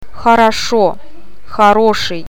O ääntyy painottomana a :n kaltaisena, e ja я i :n tai ji :n tapaisena äänteenä.
Paino voi olla eri tavulla, vaikka onkin sama kantasana.